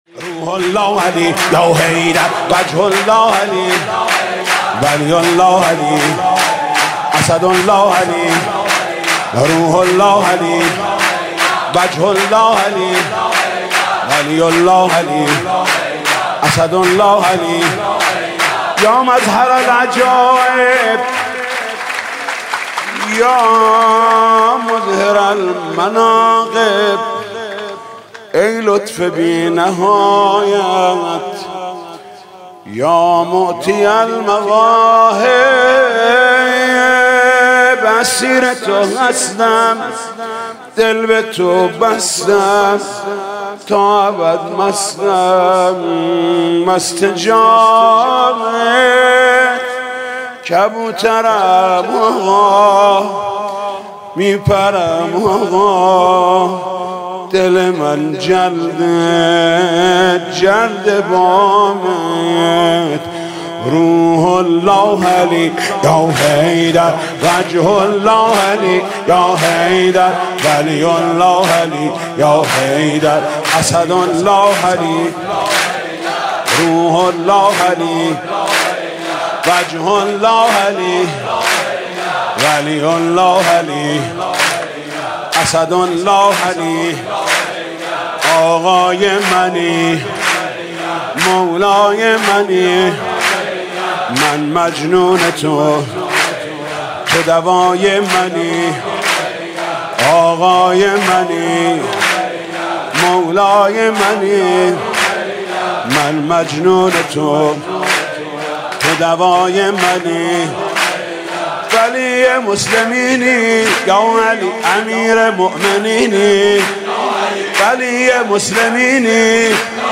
سرود: روح الله علی یا حیدر، وجه الله علی یا حیدر